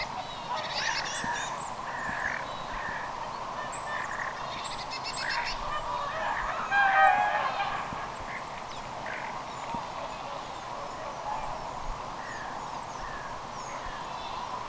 erikoista variksen ääntelyä
erikoista_varislinnun_aantako_pari_aanta_kuin_haapanan_matkintoja.mp3